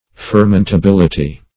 Fermentability \Fer*ment`a*bil"i*ty\, n. Capability of fermentation.